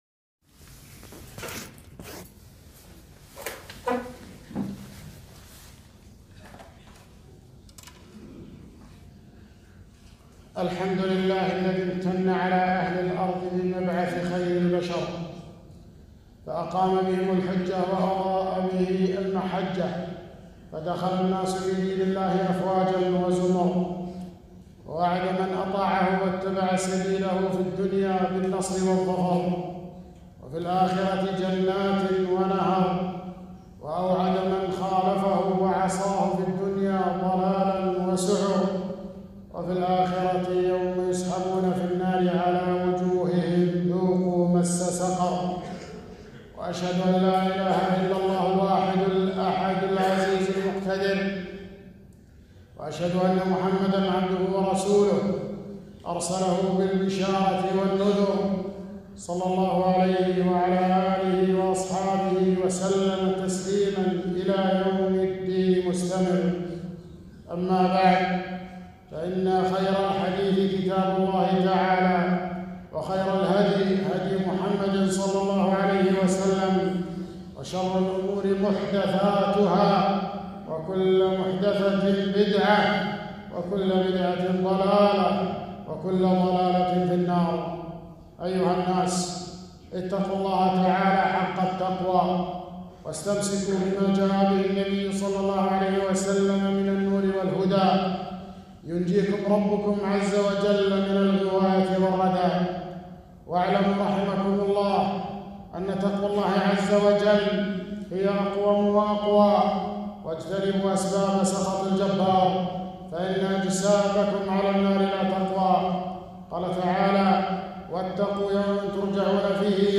خطبة - وجوب الاتباع وخطر البدعة والابتداع